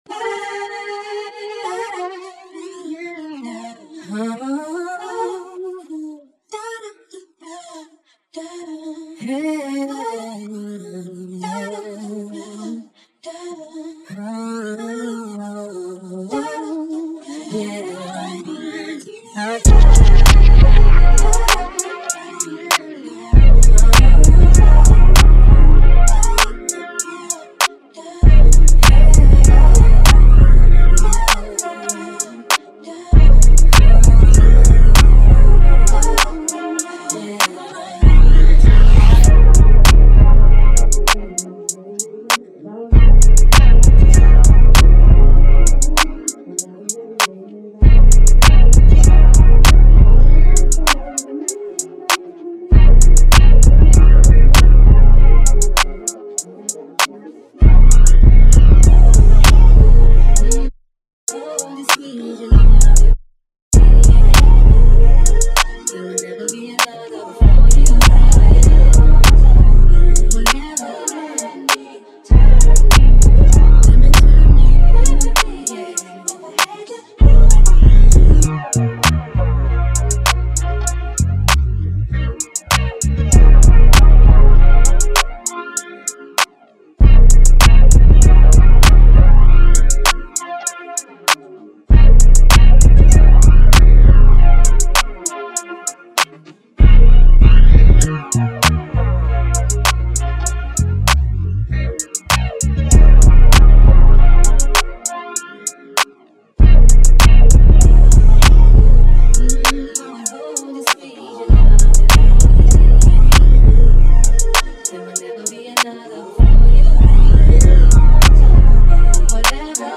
2025 in Hip-Hop Instrumentals